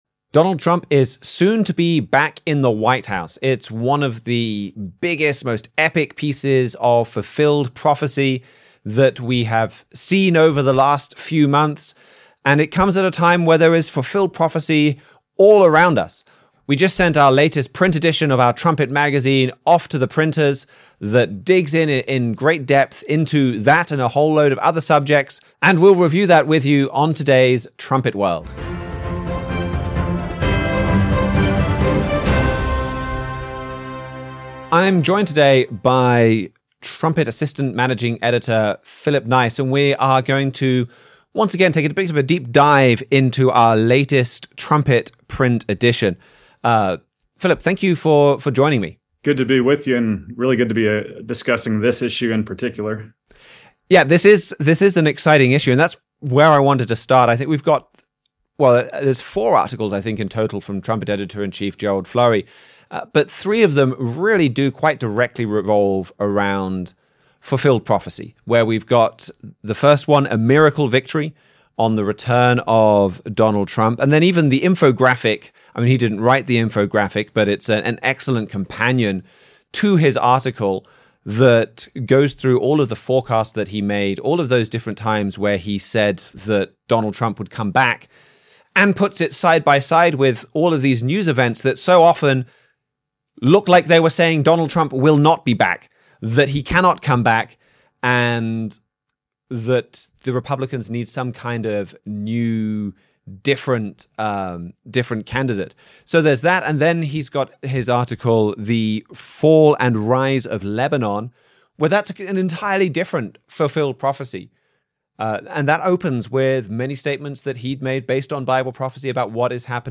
Join the discussion as Trumpet staff members compare recent news with Bible prophecy.